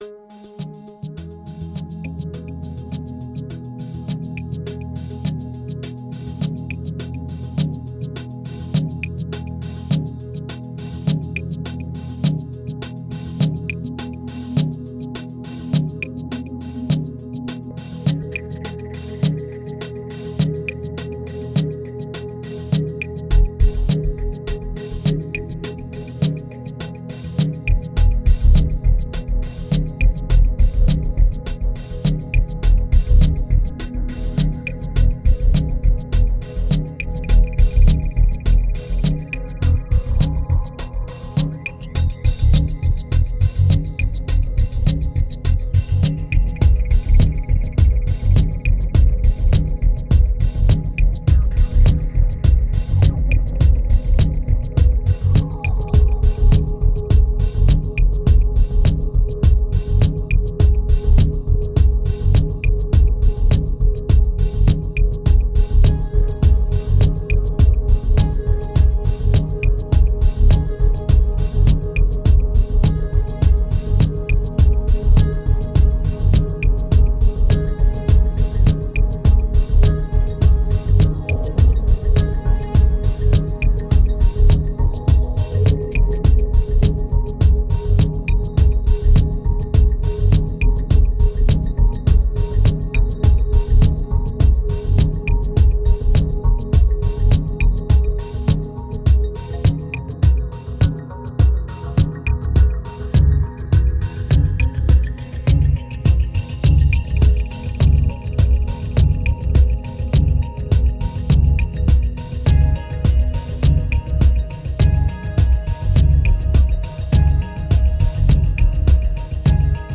1942📈 - 28%🤔 - 103BPM🔊 - 2010-11-17📅 - -144🌟